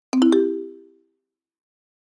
soundCorrectAnswer.mp3